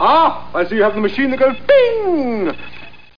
Amiga 8-bit Sampled Voice
1 channel
machinethatgoesbing.mp3